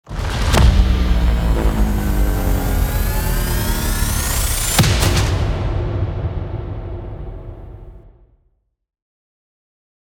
fx-downfall.mp3